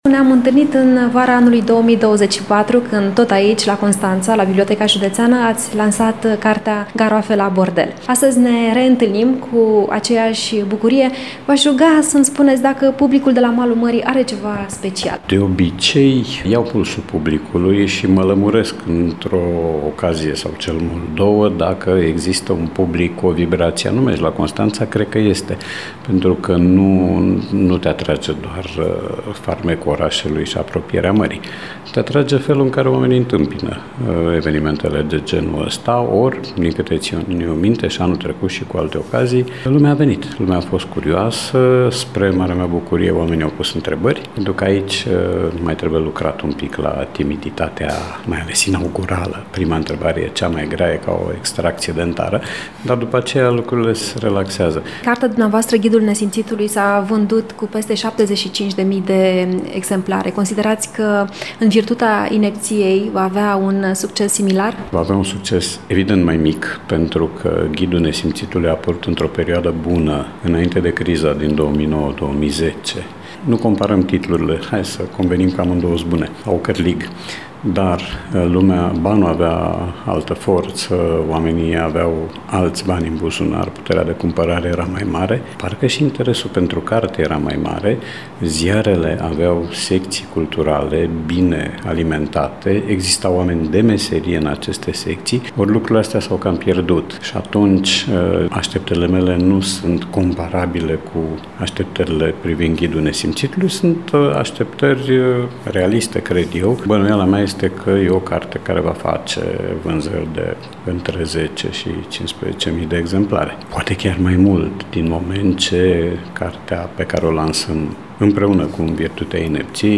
Interviu
cu scriitorul Radu Paraschivescu.